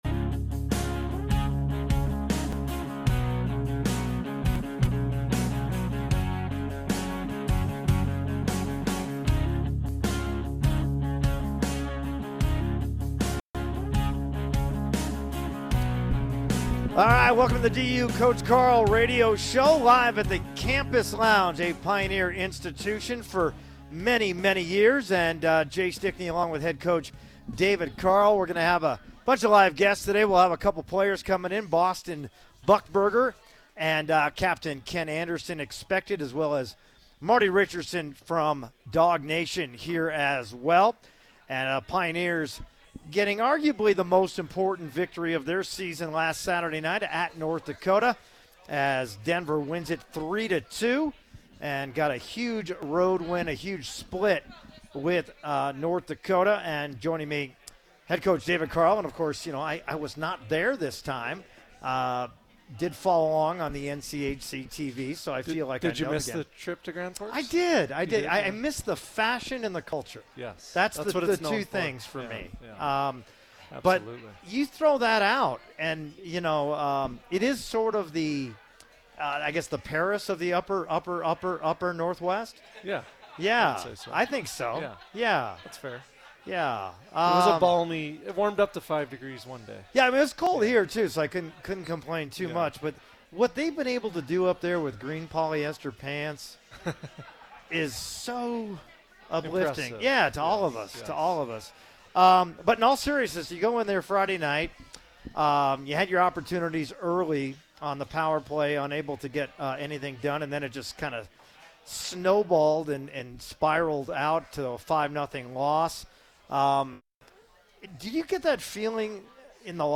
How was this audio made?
live at the Campus Lounge